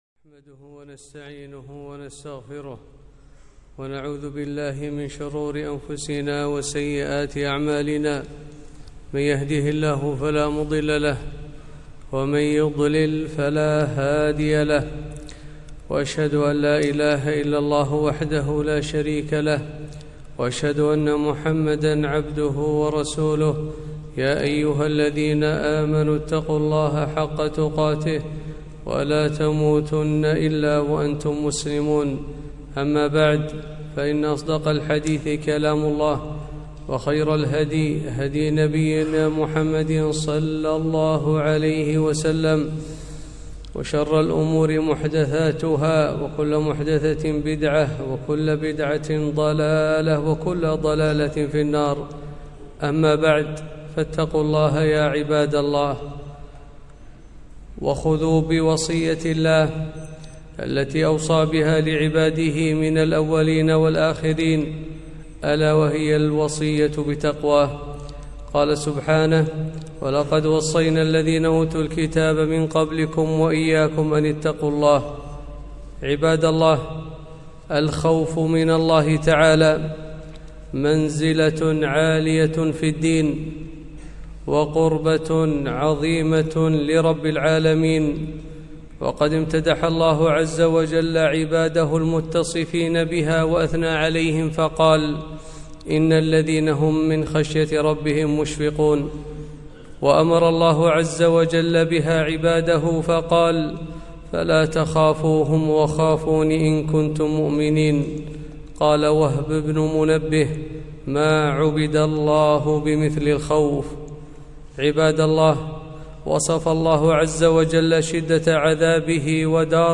خطبة - يعيش المؤمن بين الخوف والرجاء